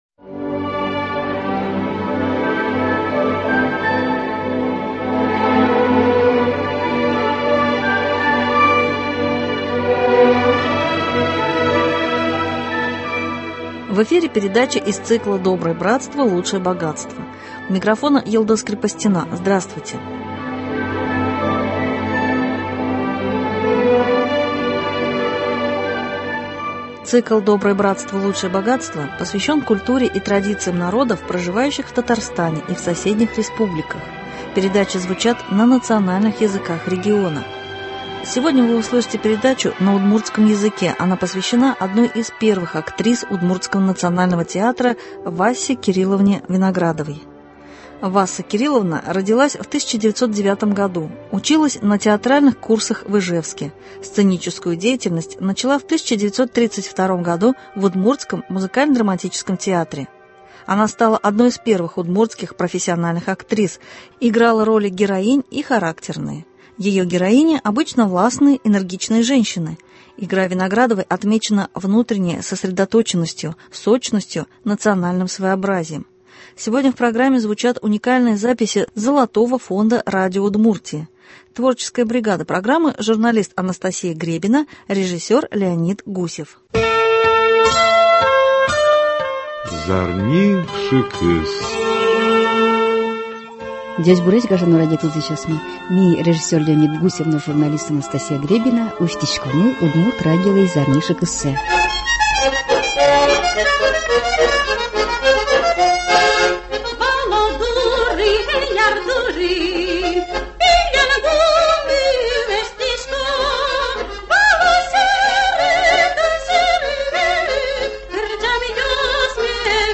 Сегодня в программе звучат уникальные записи Золотого фонда радио Удмуртии.